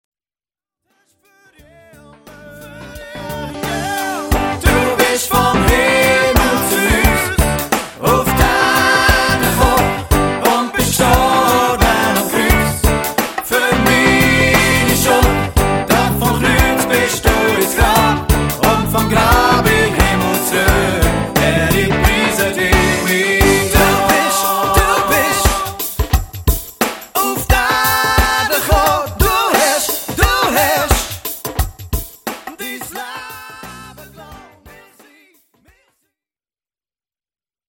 Songs (Lead Vocals)